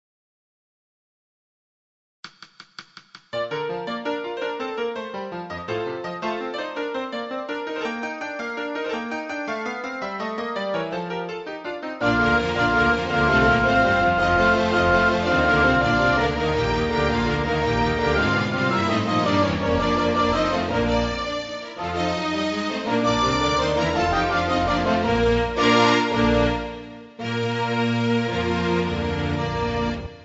INSTRUMENTAL
Classical Music